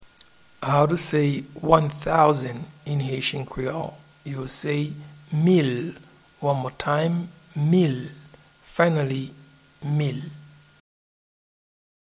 Pronunciation and Transcript:
One-thousand-in-Haitian-Creole-Mil.mp3